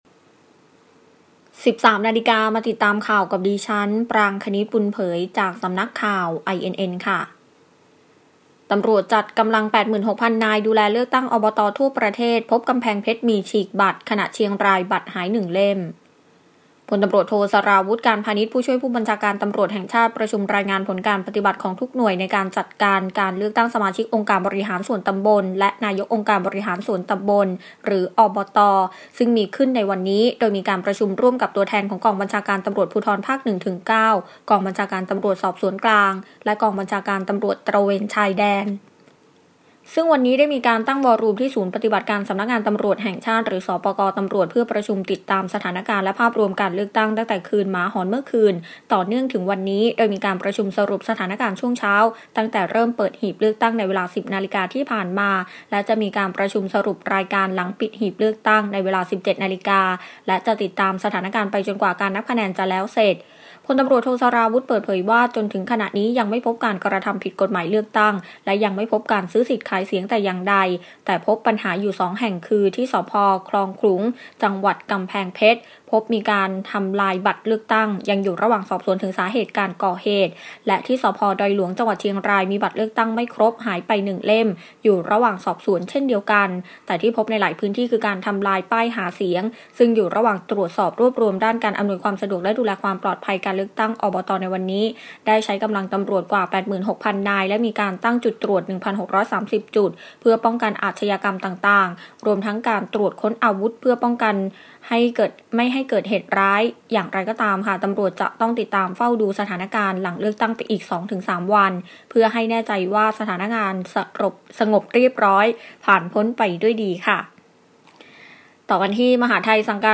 ข่าวต้นชั่วโมง 13.00 น.